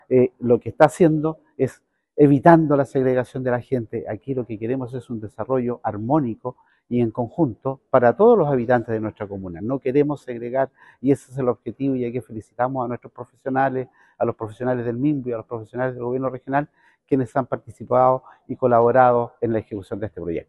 La nueva propuesta permitirá que cerca de 3.000 familias puedan acceder a una vivienda propia en este sector, como explicó el Alcalde (S) Hardy Vásquez.
En su intervención, el alcalde subrogante subrayó que, si bien la aprobación ha sido dada por el Consejo Regional, la medida aún debe ser ratificada por la Contraloría General de la República antes de entrar en plena vigencia y poder materializarse.